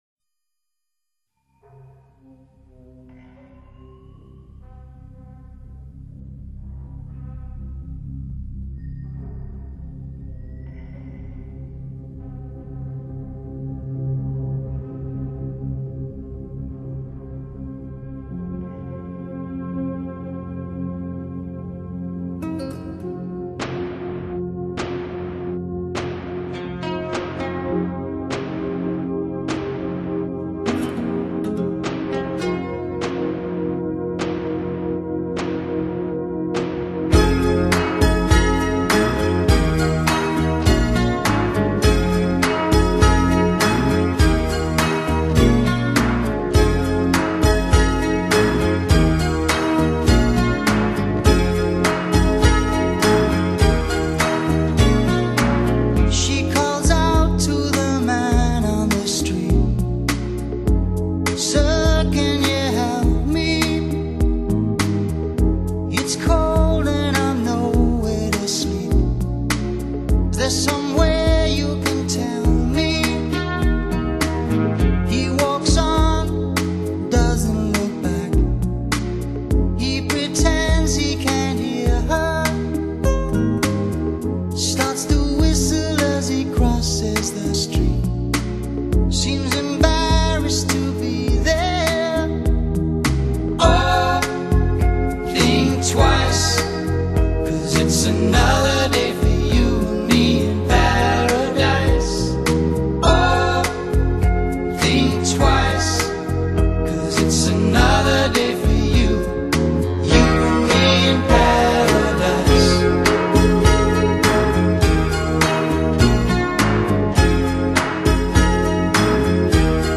那如星星在夜晚倾诉般缓慢优美的旋律， 让人们在心底浅吟低唱。